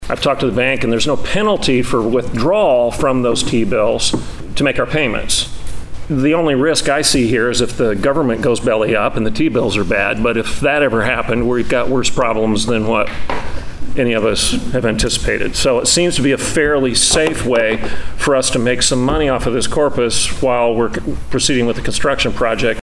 District One Commissioner for Osage County, Everett Piper spoke on Monday about how the Board could take the $ 9.3 million that is dedicated to the annex project and invest that into U.S. Treasury Bills